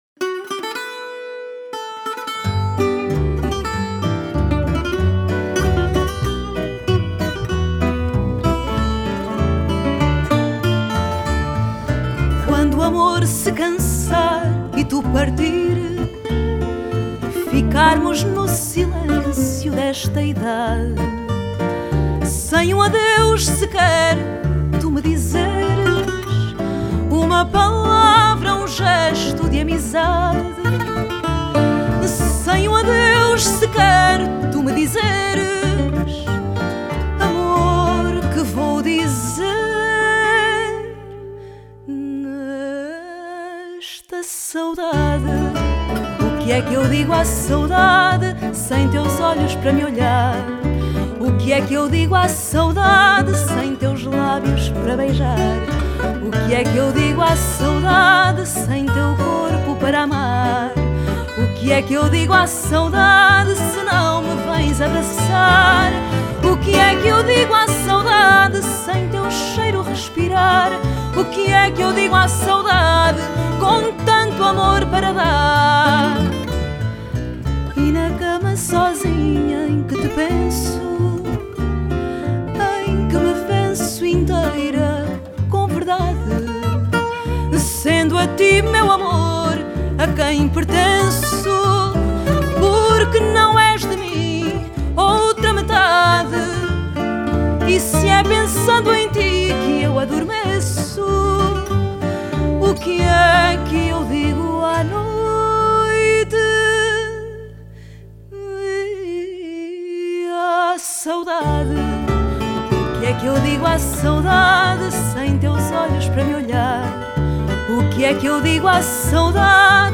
Genre: Fado